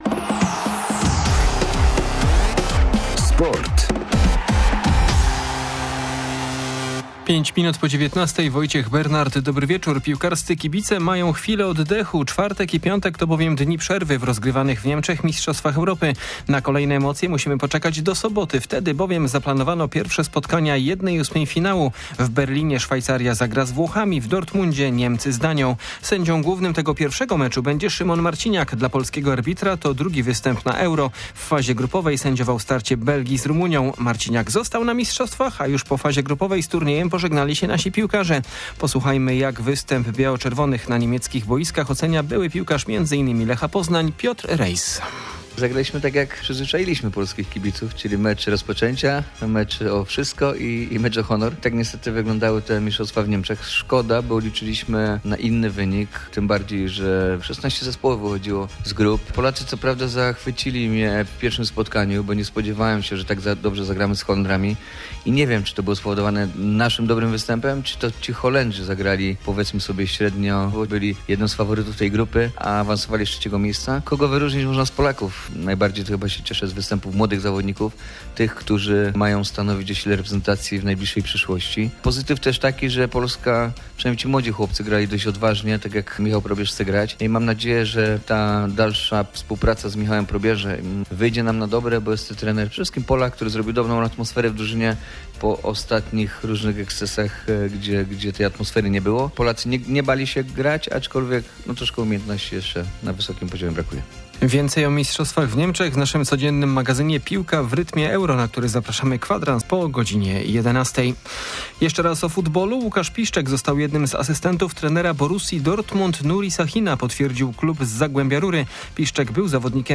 27.06.2024 SERWIS SPORTOWY GODZ. 19:05